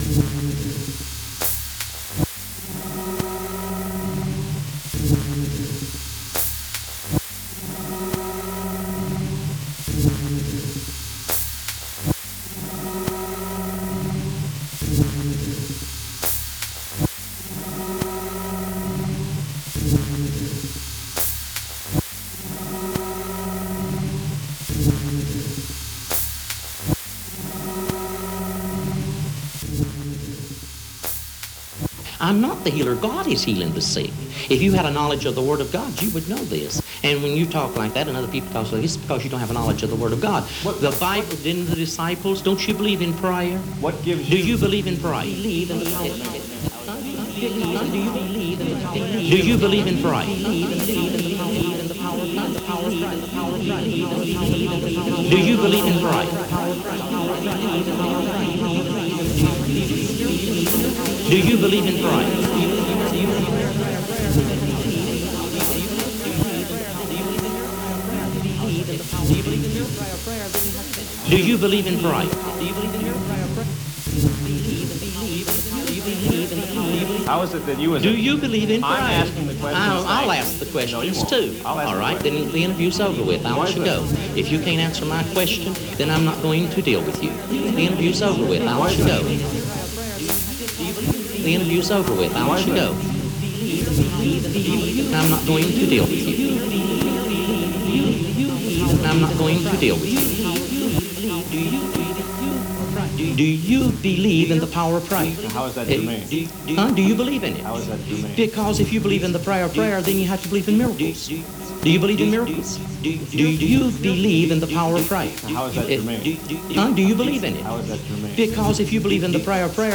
Recorded Live at ‘The Residence’s Basement’ – July 12, 2024